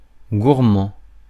Ääntäminen
IPA: /ɡuʁ.mɑ̃/